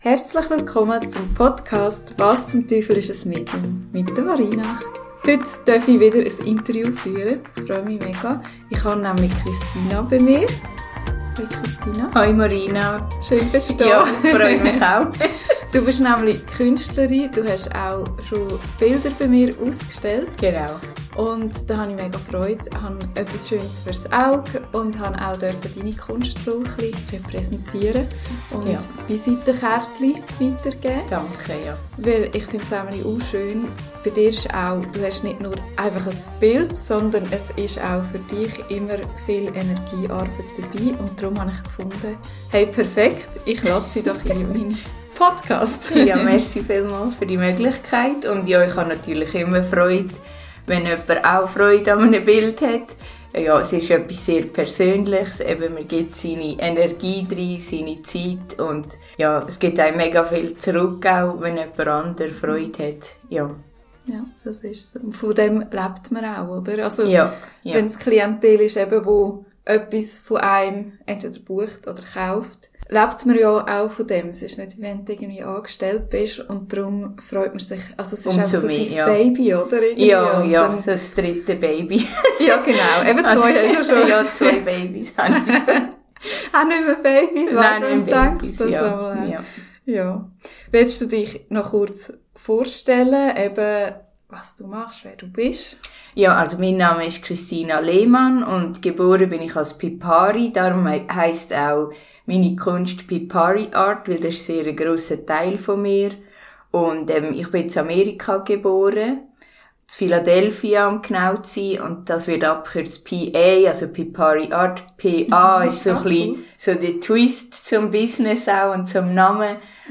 Es wird ehrlich, tief, inspirierend und auch richtig lustig.